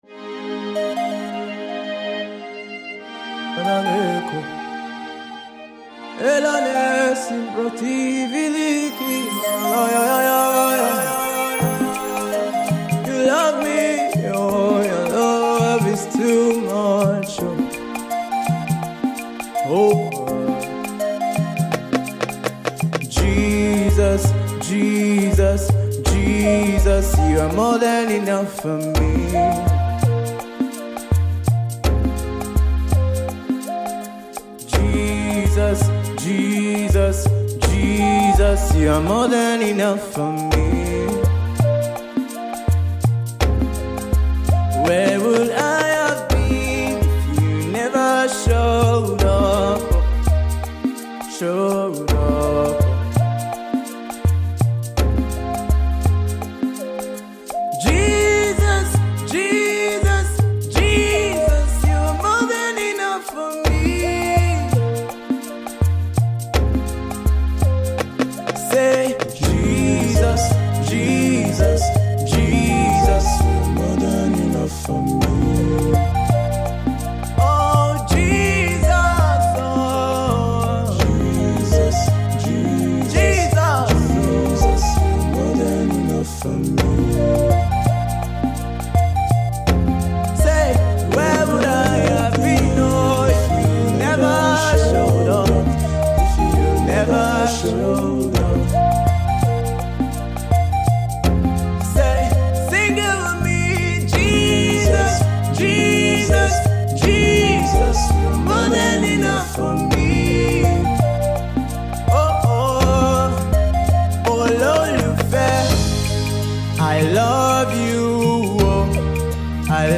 soul and urban fusion laced with the Afropop instrumental